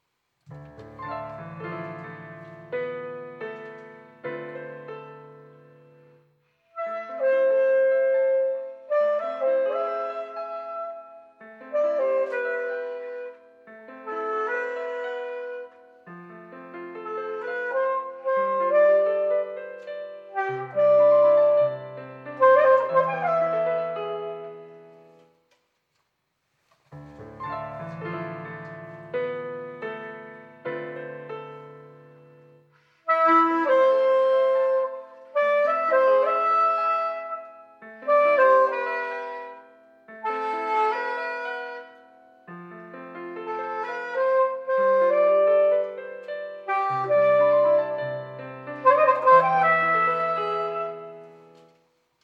Which one is the Chinese soprano saxophone?
The first track is the Chinese saxophone.